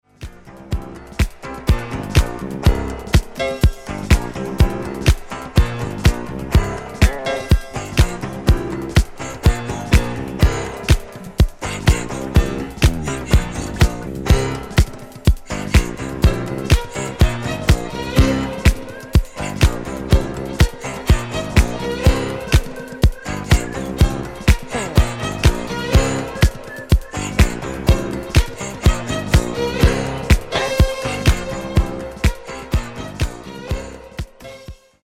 Genere:   Rare Disco Soul Funk